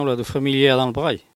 Patois
Locution